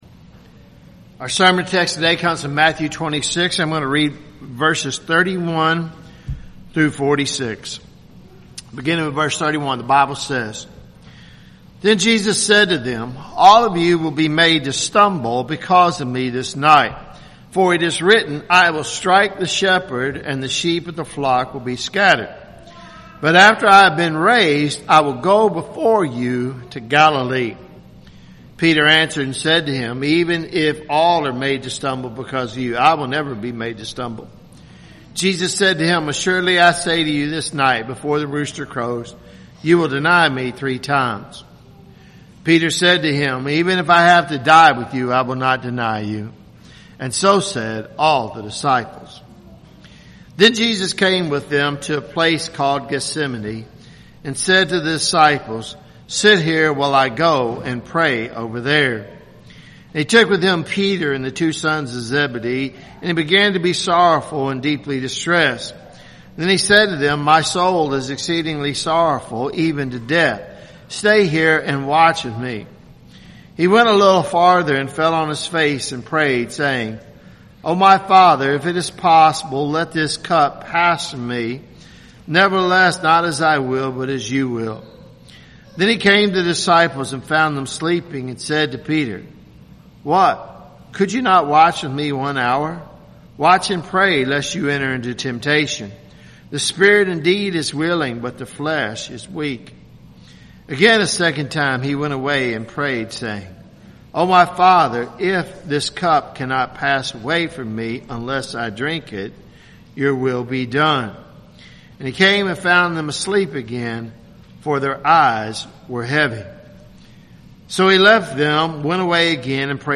at Christ Covenant Presbyterian Church, Lexington, Ky.